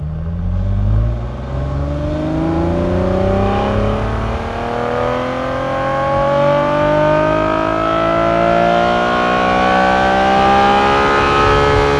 rr3-assets/files/.depot/audio/Vehicles/v12_07/v12_07_accel.wav
v12_07_accel.wav